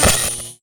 etfx_shoot_lightning.wav